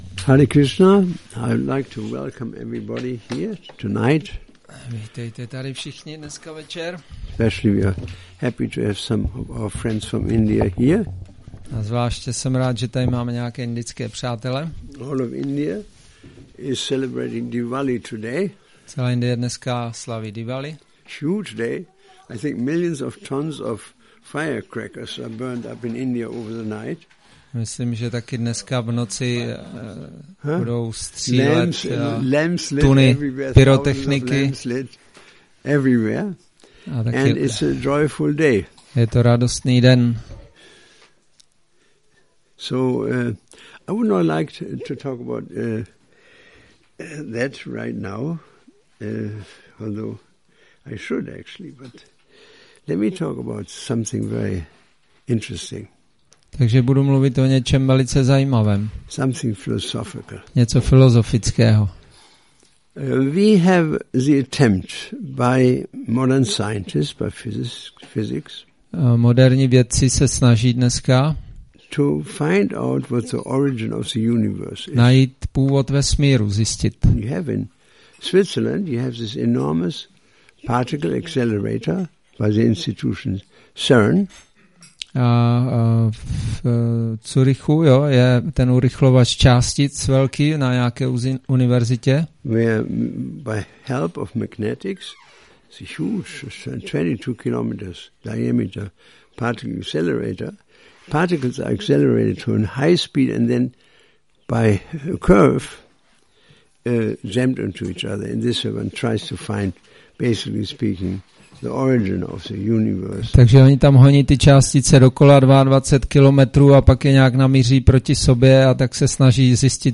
Přednáška Origin of the Universe